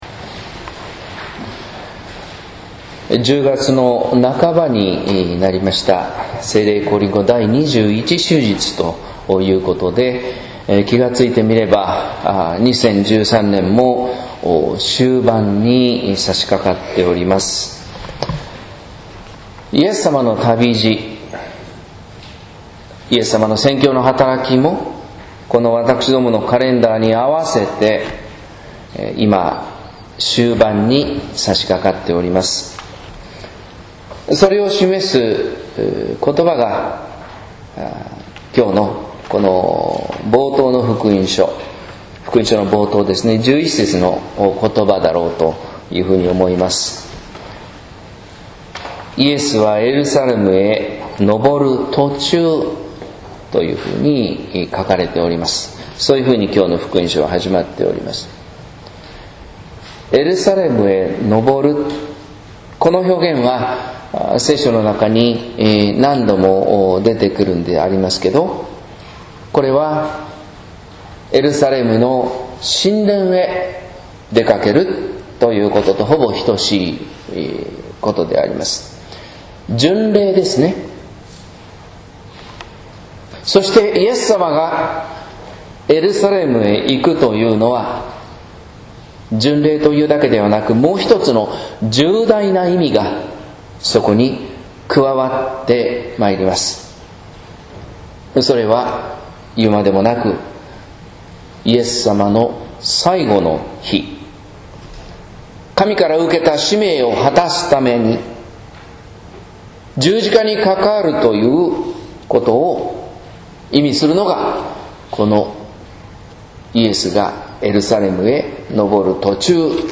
説教「知って信じる、信じて知る」（音声版）